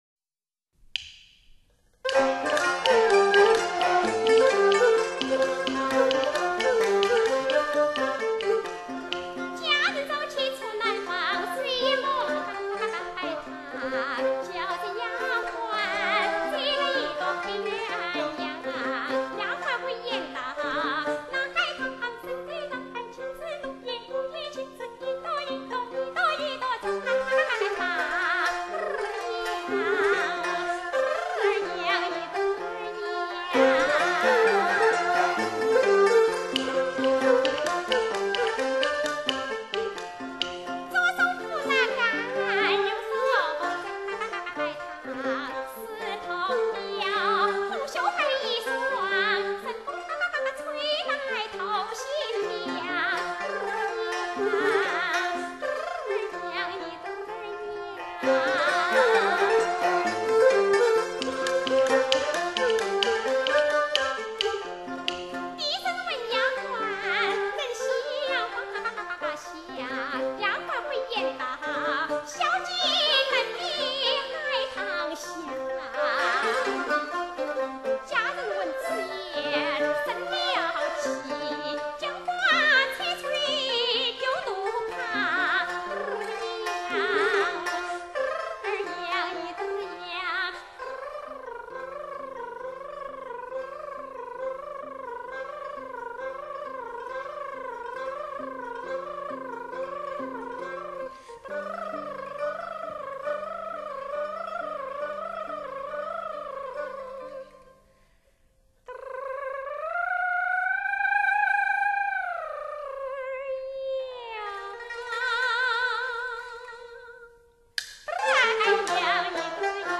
是一首四川清音。
该曲可用于考察音箱的声象定位，如应感觉到竹鼓的位置应比乐器高，而人声的位置又比竹鼓高